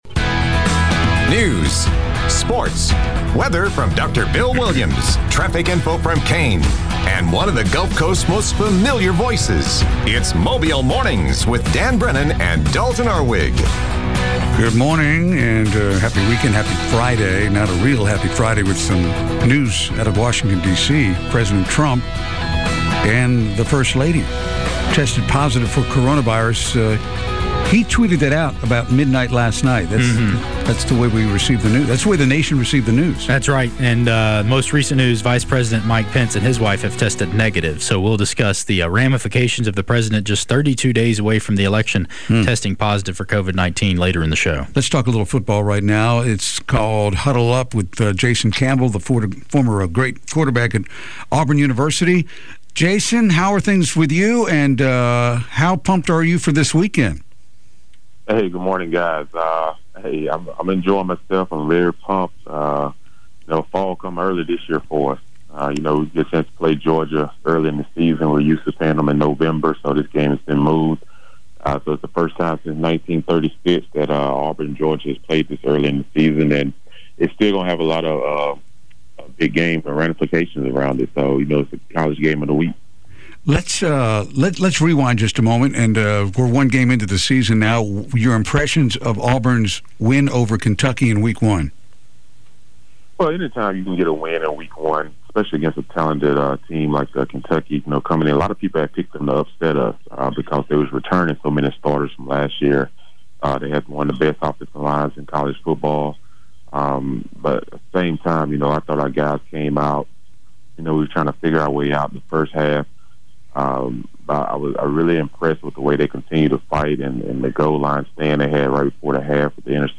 report on local news and sports
reports on traffic conditions